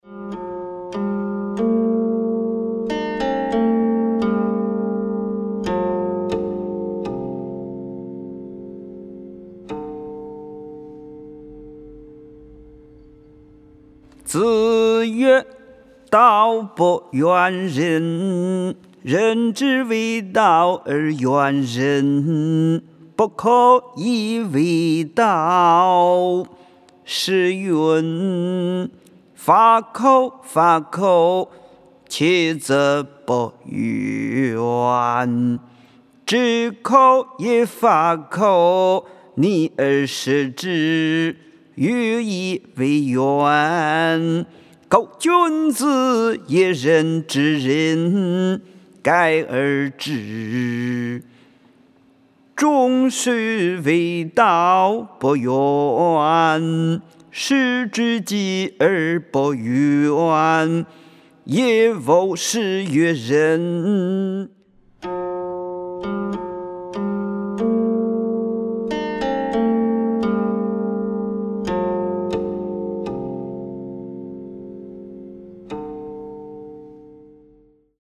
吟誦